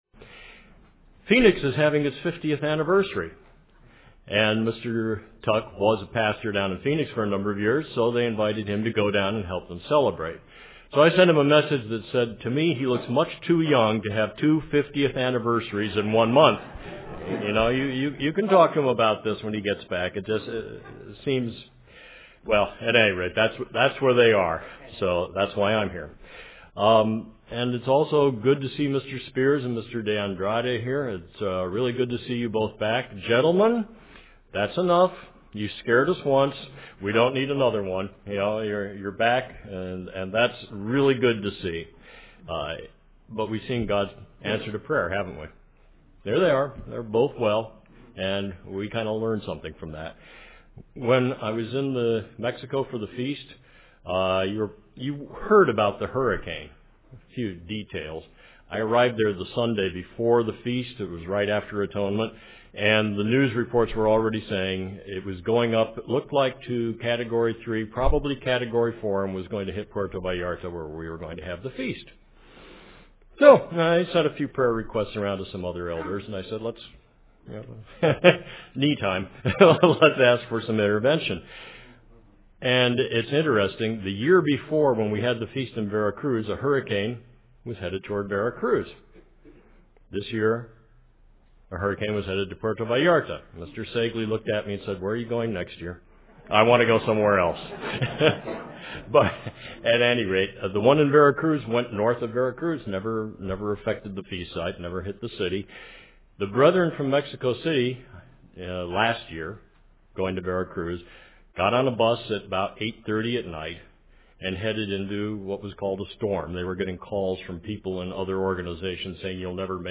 UCG Sermon Notes